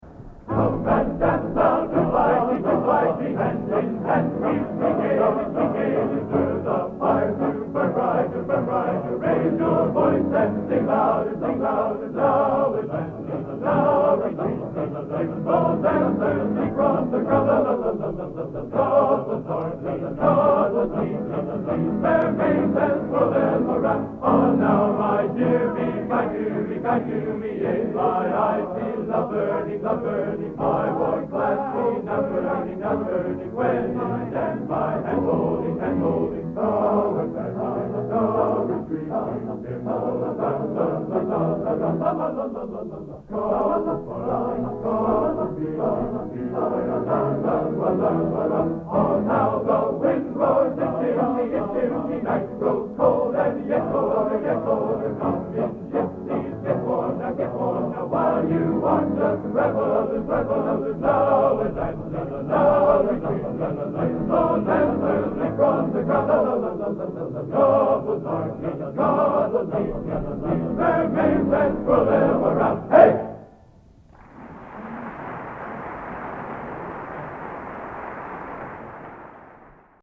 Location: West Lafayette, Indiana
Genre: International Traditional | Type: End of Season